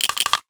NOTIFICATION_Rattle_04_mono.wav